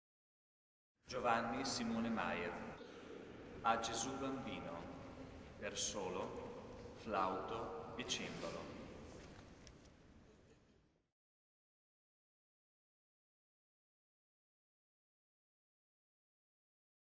Elevazioni Musicali > 2000 > 2001
S. Alessandro in Colonna